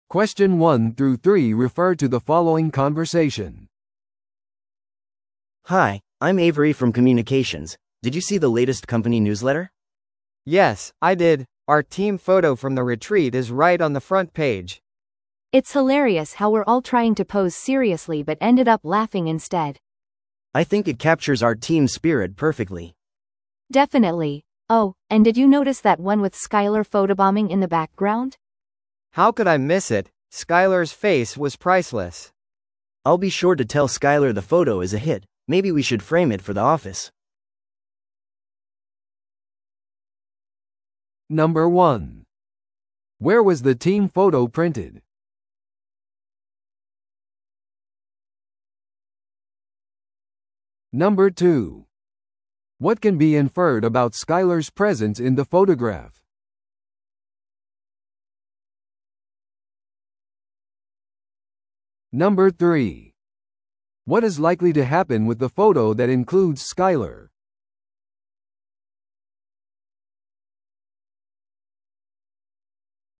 TOEICⓇ対策 Part 3｜社内ニュースレターのチーム写真について – 音声付き No.144